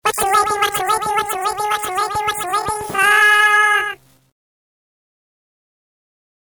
3. SUONERIE TRUE TONES